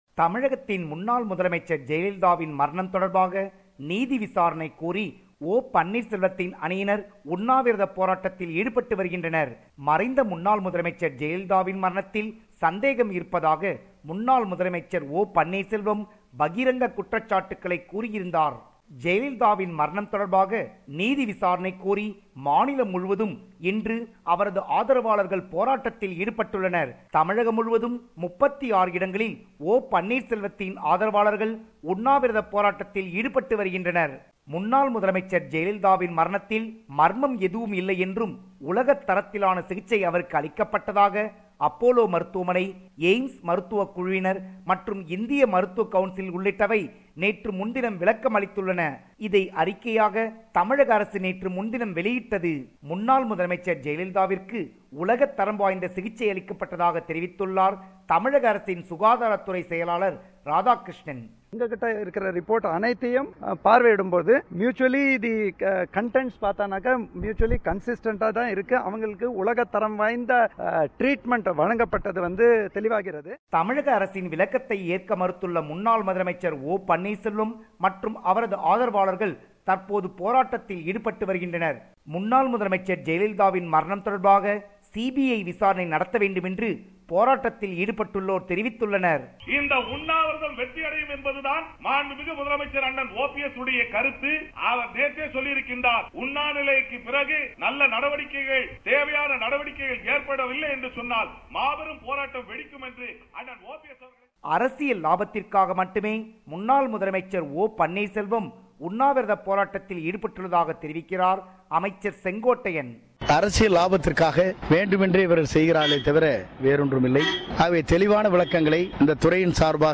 compiled a report focusing on major events/news in Tamil Nadu / India.